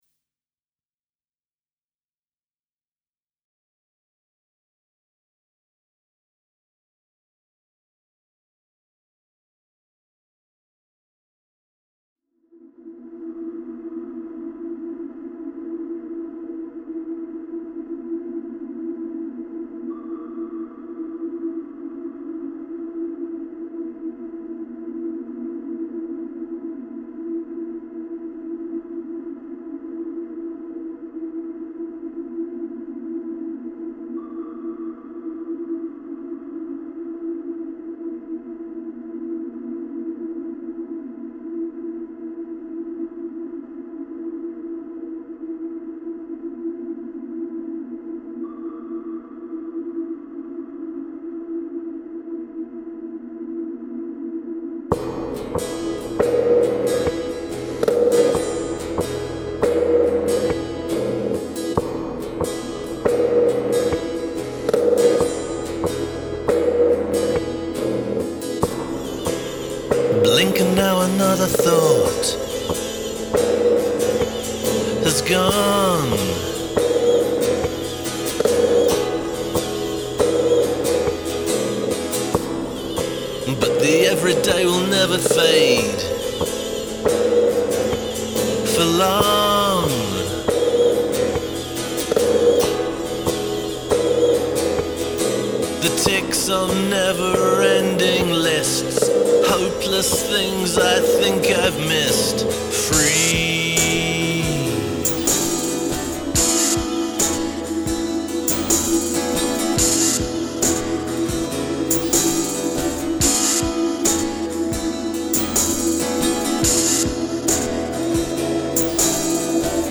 Ambient-Prog-Psych-Folk-Metal
Very Rough Mix